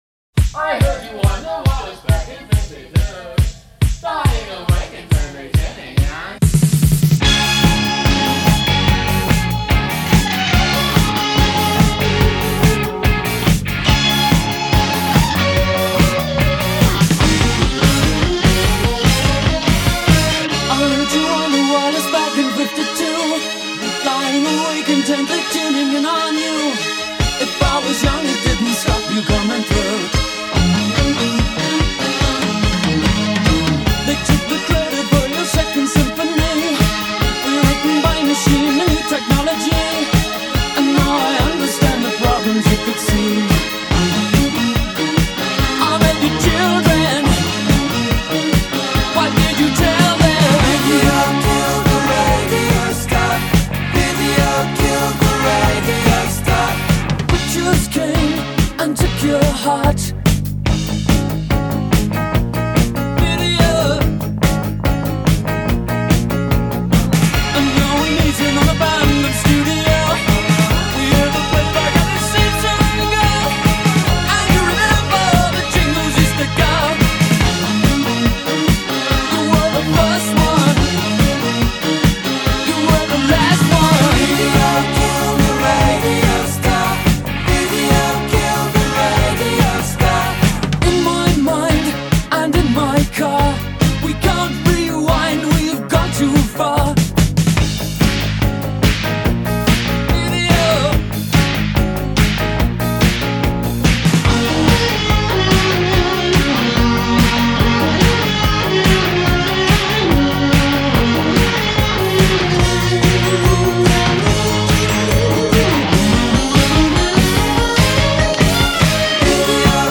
Recorded live upstairs at Mike’s Farm, 3/1/2014